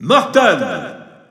Announcer pronouncing Morton in French (Europe).
Category:Bowser Jr. (SSBU) Category:Announcer calls (SSBU) You cannot overwrite this file.
Morton_French_EU_Announcer_SSBU.wav